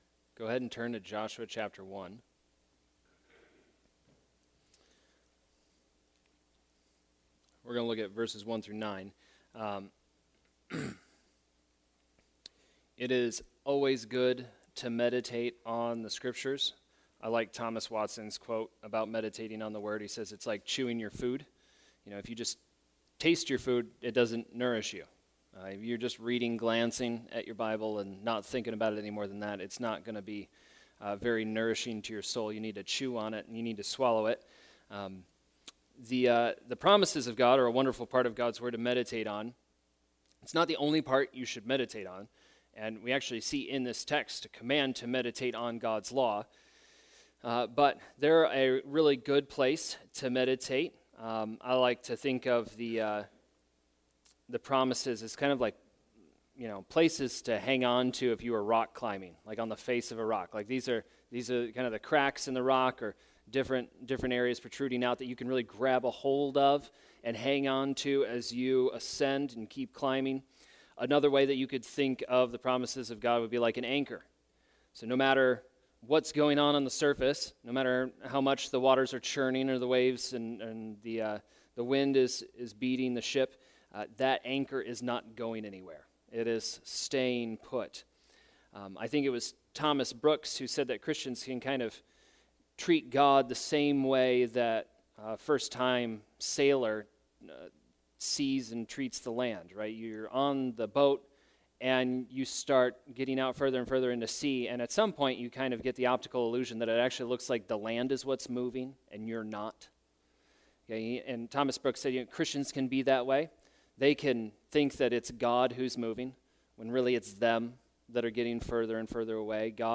Family Night Sermons